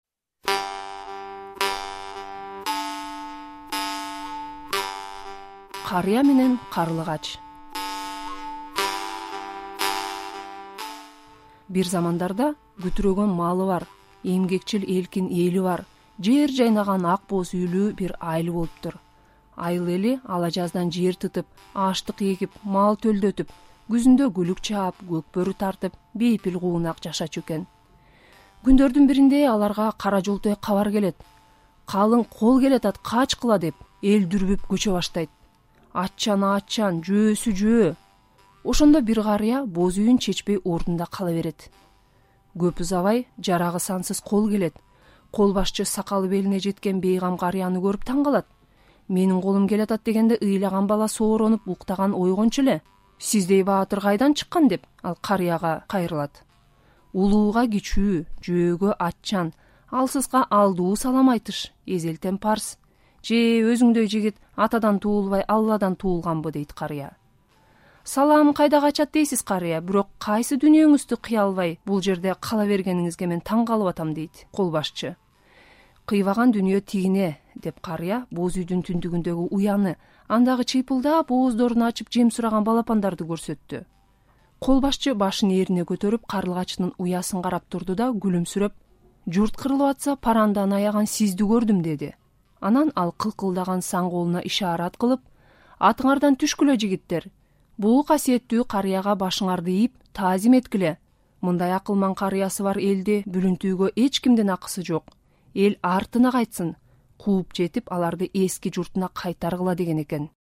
"Карыя менен Карлыгач" Окуган